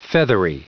Prononciation du mot feathery en anglais (fichier audio)
Prononciation du mot : feathery